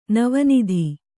♪ nava nidhi